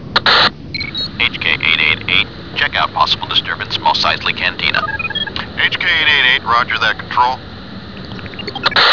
Y cómo último toque genial, antes de los títulos de crédito a ritmo de "Bad Boys", oímos las frecuencias de las tropas imperiales ordenando a la escuadra más cercana que se pase a revisar "un altercado en la Cantina de Mos Eisley".